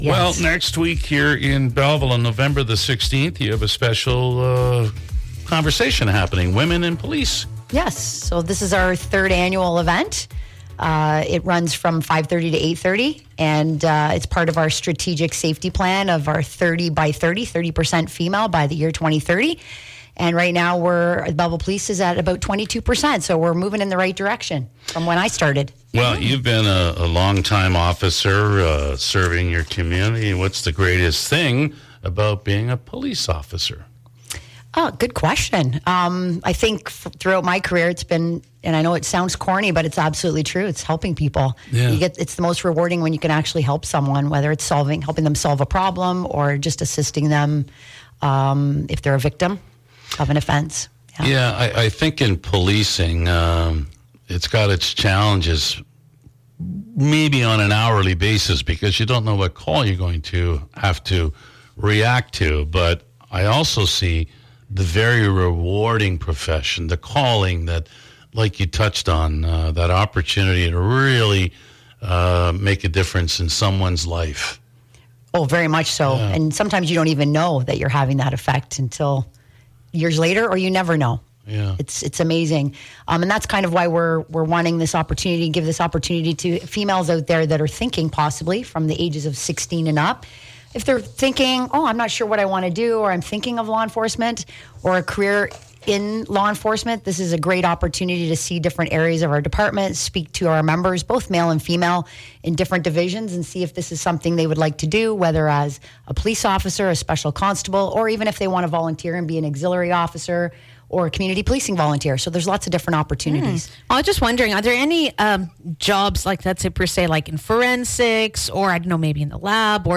popped into Studio 97 to raise awareness on jobs for women in police